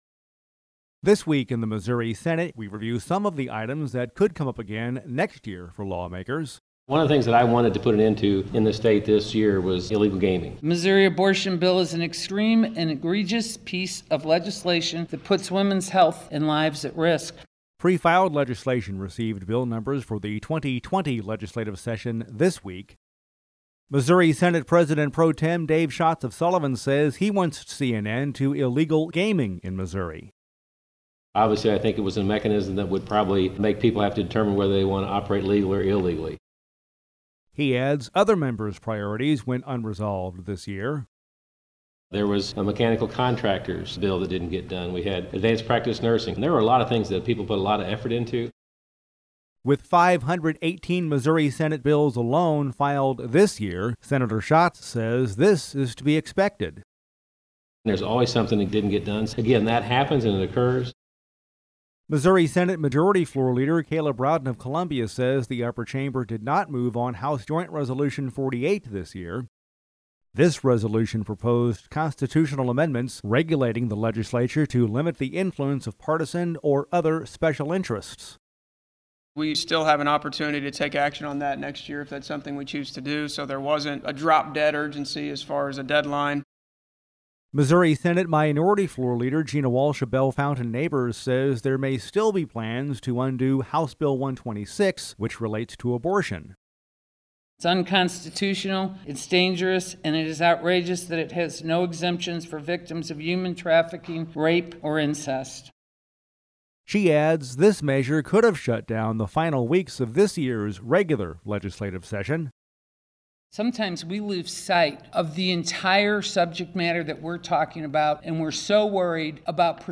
Dec. 6: This Week in the Missouri Senate reviews some unresolved matters from the 2019 legislative session that could be revisited next year. We’ve included actualities from Missouri Senate President Pro Tem Dave Schatz, R-Sullivan; Missouri Senate Majority Floor Leader Caleb Rowden, R-Columbia; Missouri Senate Minority Floor Leader Gina Walsh, D-Bellefontaine Neighbors; and Sen. Scott Sifton, D-Affton, in this feature report